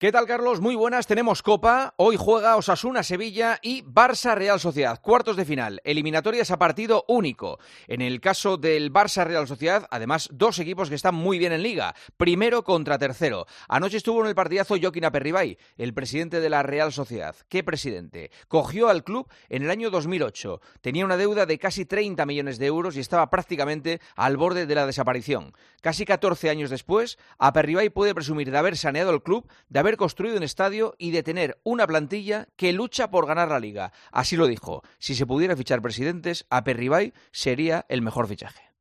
El presentador de 'El Partidazo de COPE' analiza la actualidad deportiva en 'Herrera en COPE'